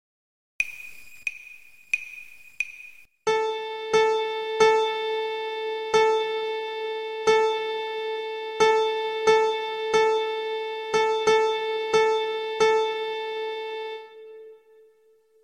Each question will be played five times.
Part 1 Rhythm
1) 4 4 Time, four measures long
Uses half note, dotted quarter, quarter, and eighth notes.